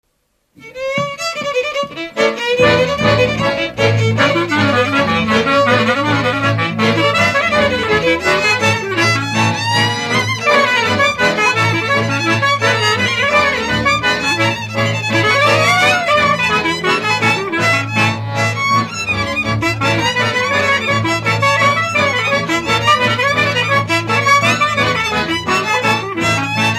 Dallampélda: Hangszeres felvétel
Erdély - Udvarhely vm. - Homoródalmás
hegedű
klarinét
harmonika
bőgő
Műfaj: Csűrdöngölő
Stílus: 8. Újszerű kisambitusú dallamok